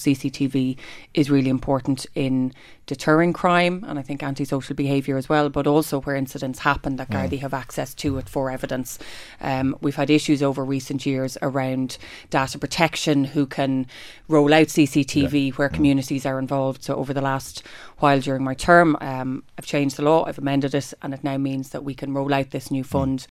Minister McEntee says everyone has a right to feel safe and be safe in their communities…………….